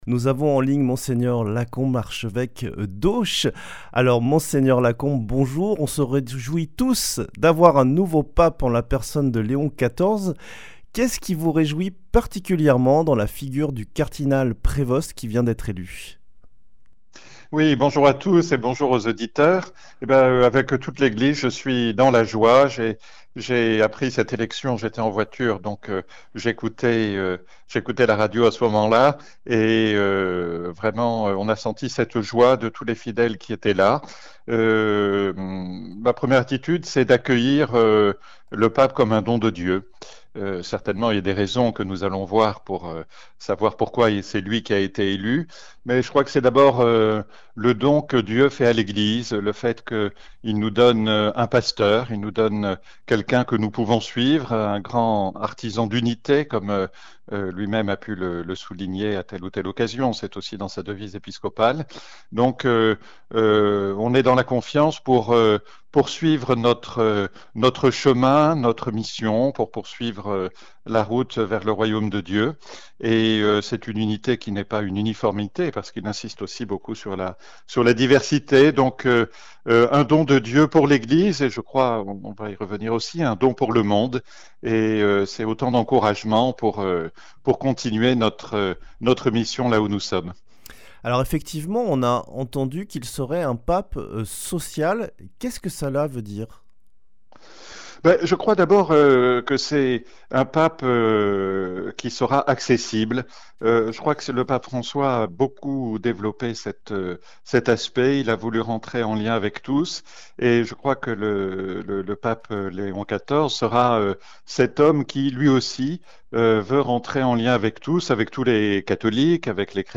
Ce vendredi 9 mai, au lendemain de l’élection du nouveau pape Léon XIV, Radio Présence donne la parole à Monseigneur Bertrand Lacombe, archevêque d’Auch. Dans cet entretien, il partage avec nous sa joie, ses intuitions et ses espérances à l’heure où l’Église universelle entre dans un nouveau pontificat, sous la conduite du cardinal Robert Prevost, désormais successeur de Pierre.